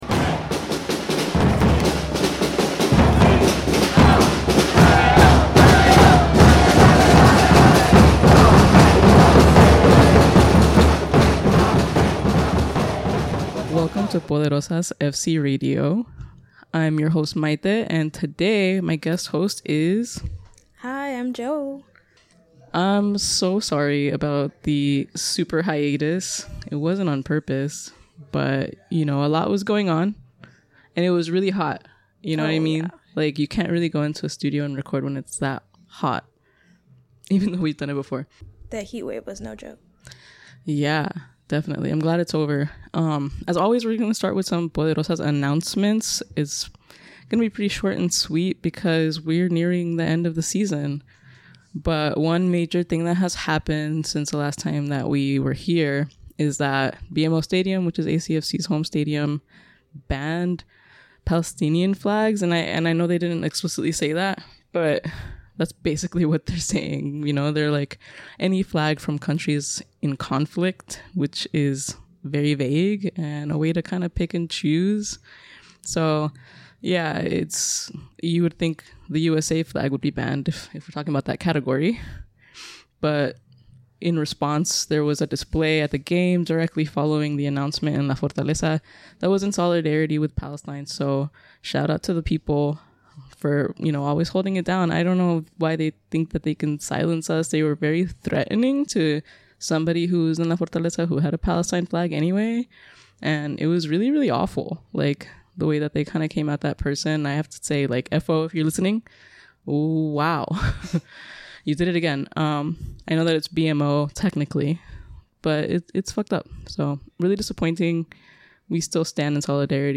A special thank you to Espacio 1839 for the studio space.